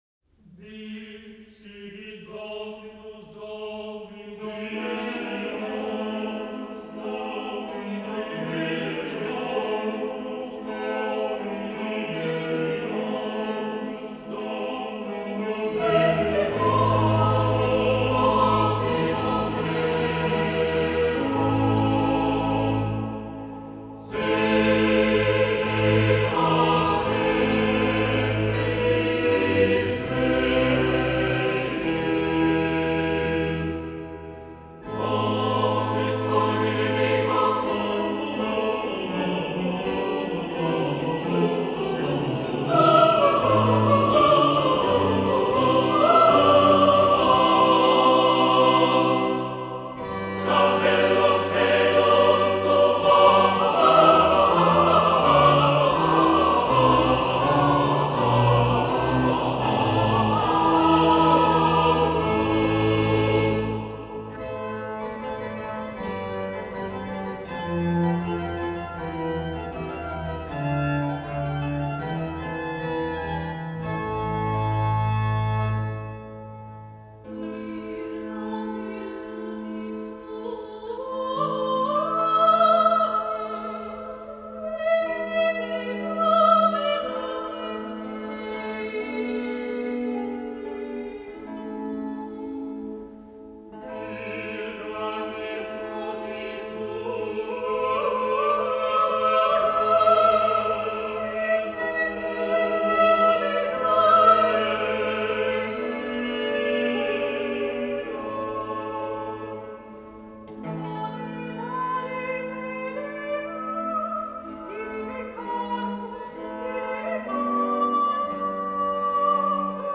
Garīgā vokālā mūzika
Mūzikas ieraksts